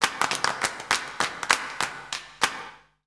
Index of /90_sSampleCDs/Voices_Of_Africa/VariousPhrases&Chants
22_Clapping3.WAV